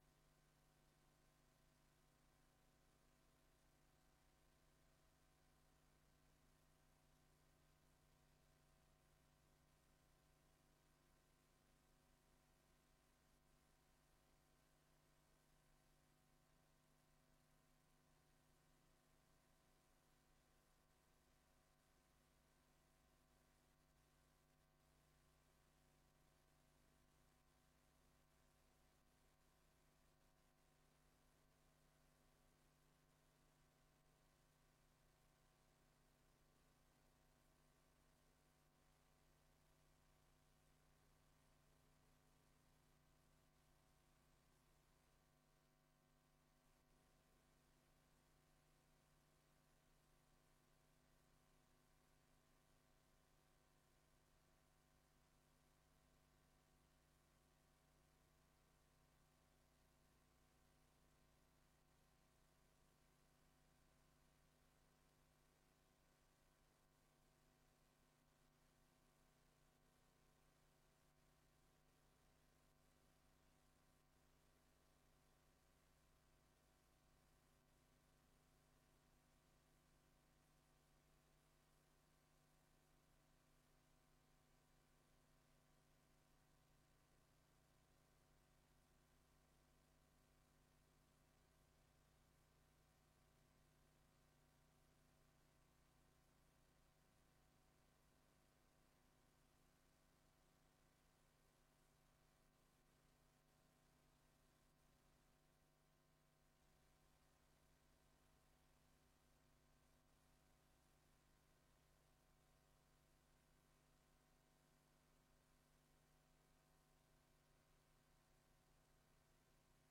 Raadsbijeenkomst 10 oktober 2023 19:00:00, Gemeente Tynaarlo